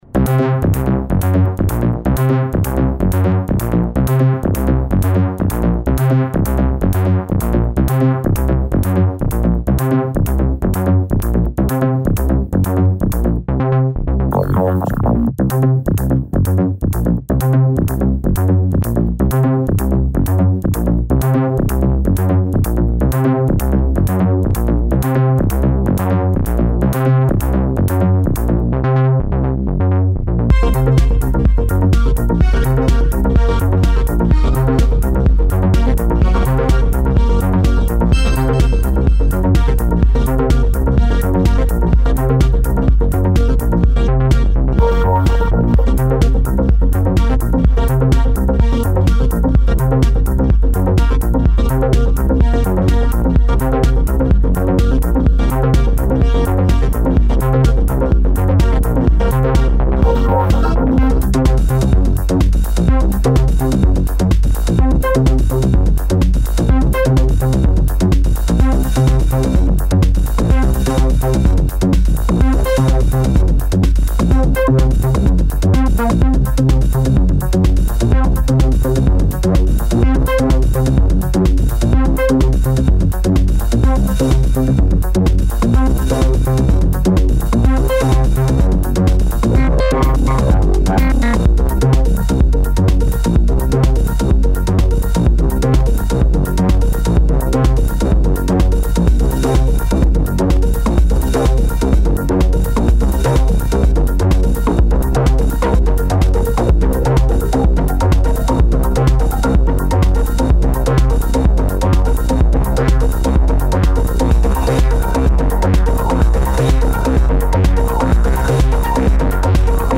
dance/electronic
Techno
Industrial
Electro